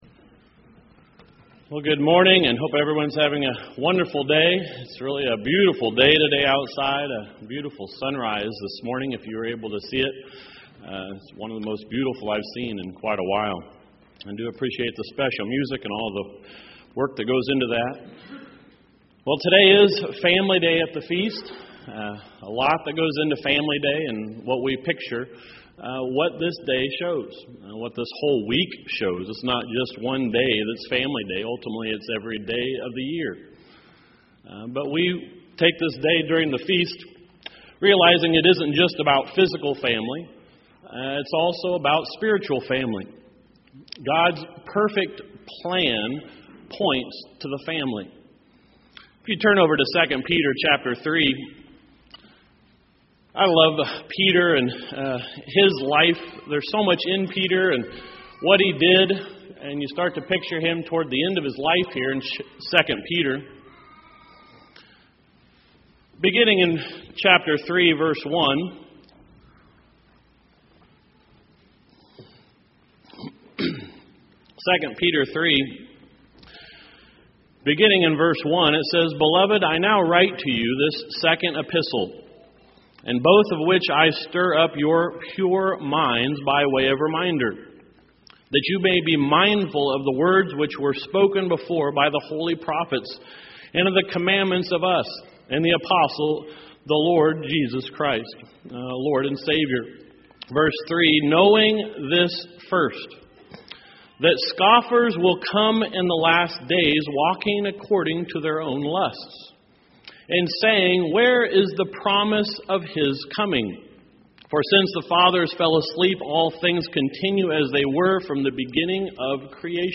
This sermon goes through the scriptures about God's plan and the importance of the family in God's plan.
This sermon was given at the Gatlinburg, Tennessee 2013 Feast site.